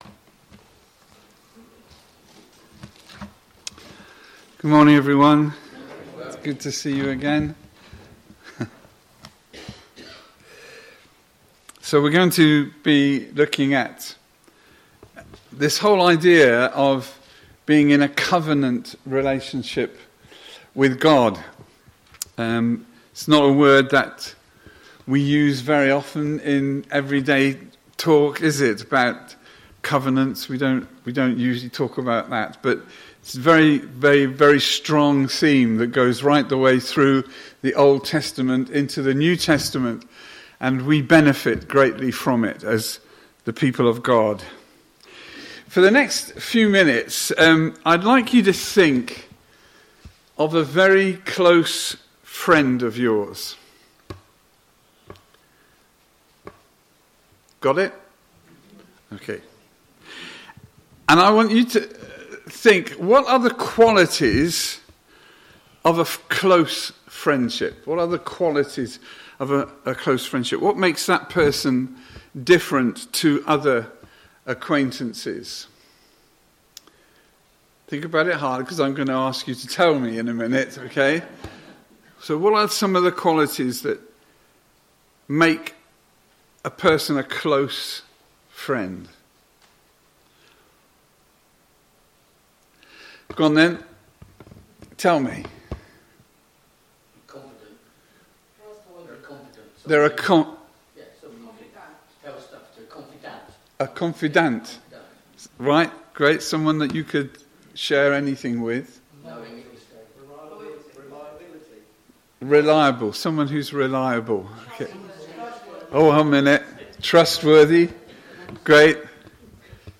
Sunday-Service-16.02.2025.mp3